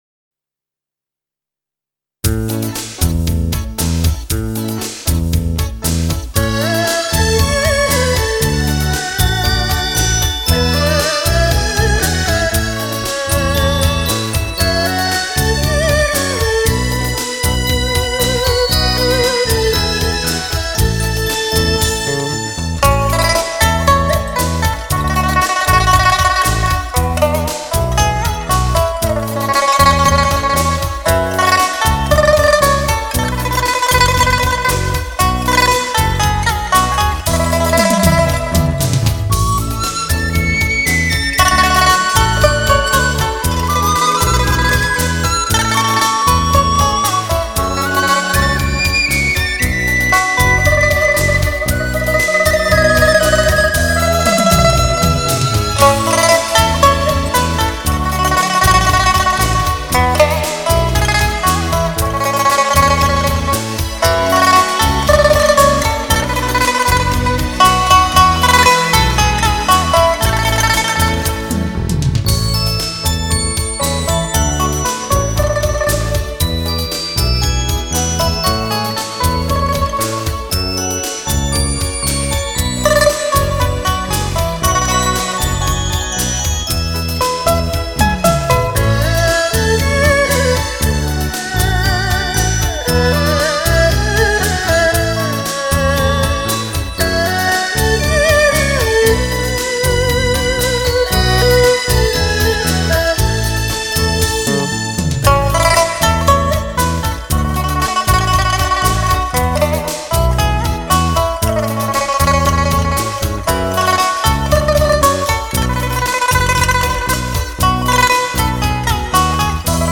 琵琶独奏
他的演奏激情与韵味并重，擅长体现不同流派的特点和风格。